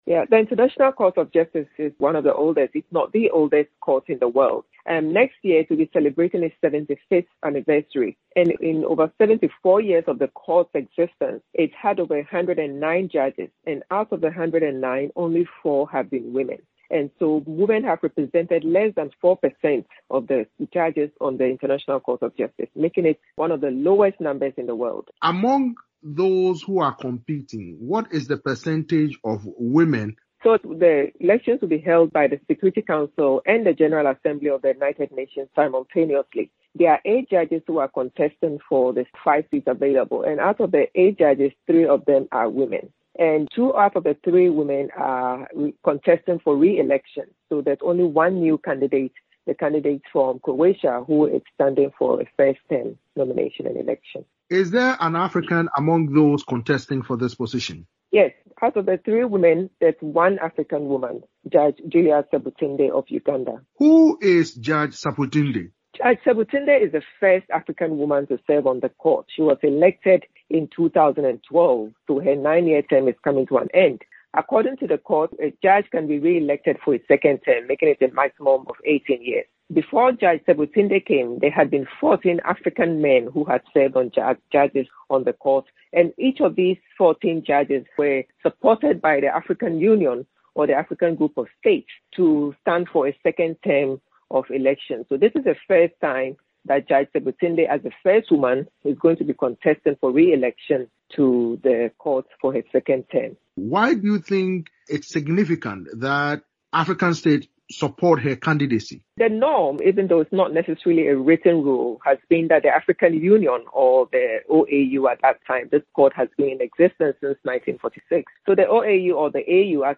spoke with analyst